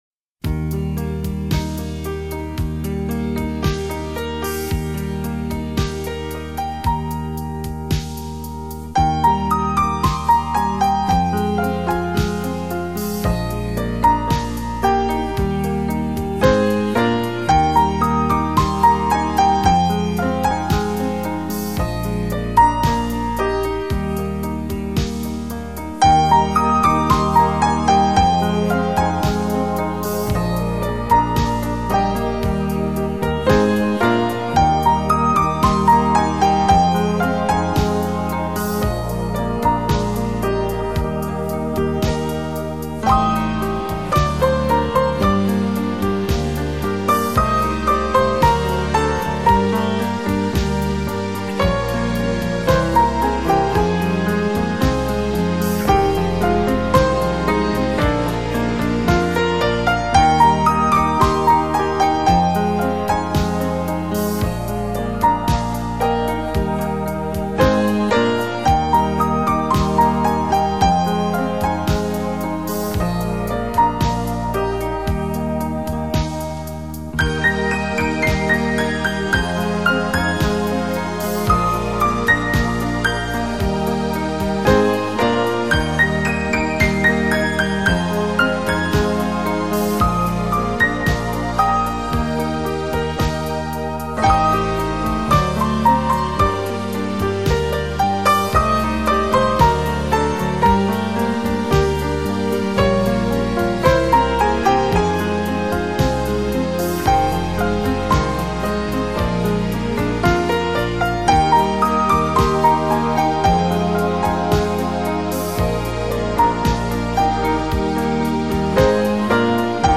来自瑞士一尘不染清新的天籁音乐